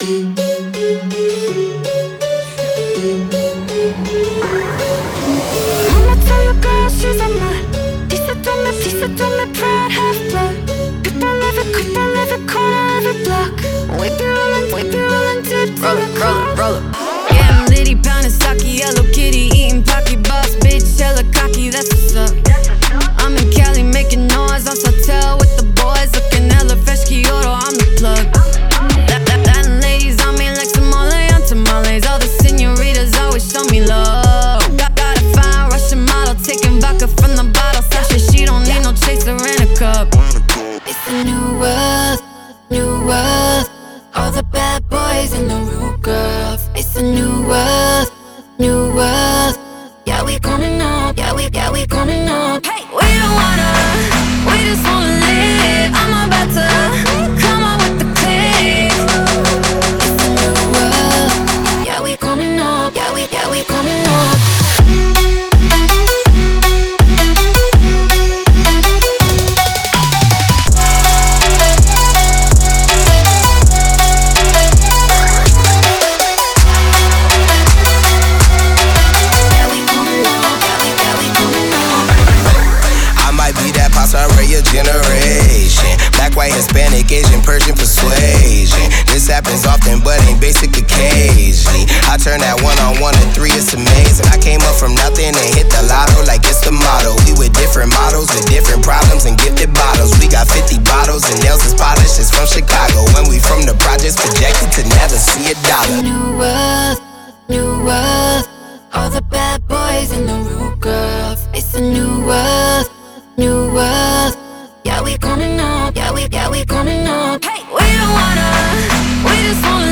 • Жанр: Electronic, EDM